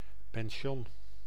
Ääntäminen
IPA: [o.bɛʁʒ]